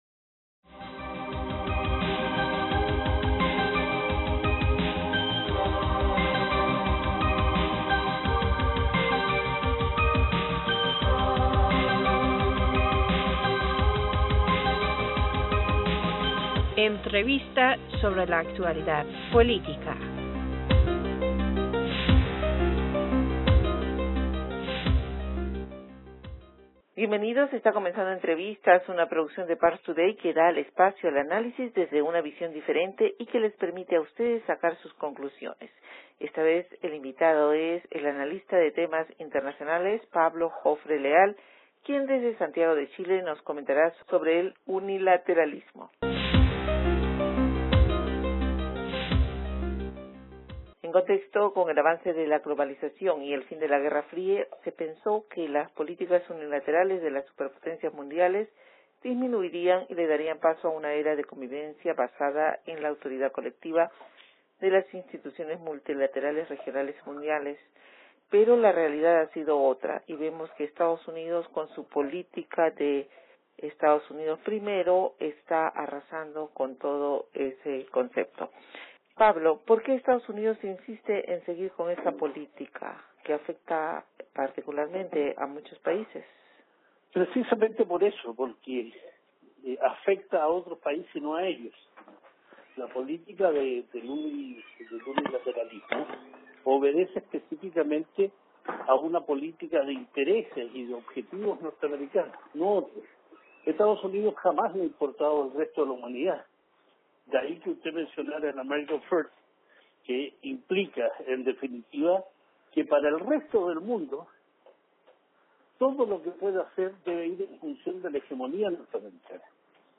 Entrevistas
Esta vez el invitado es el analista de temas internacionales